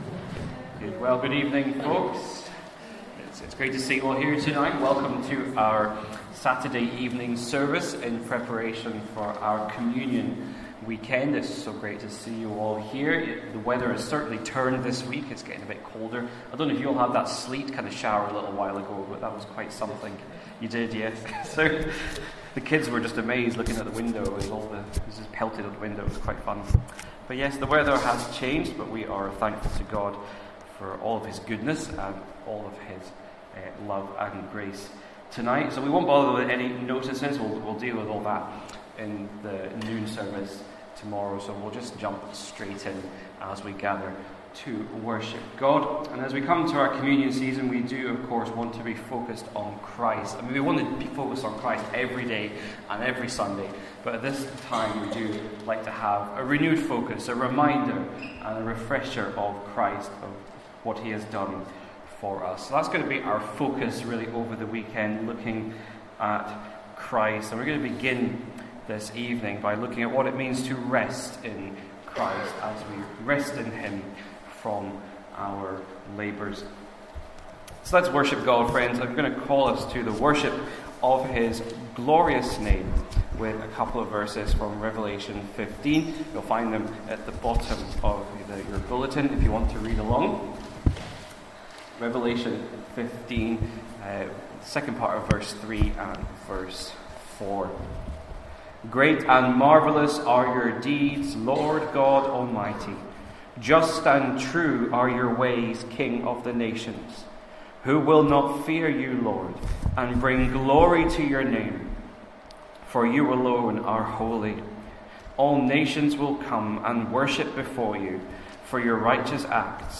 Saturday Evening Service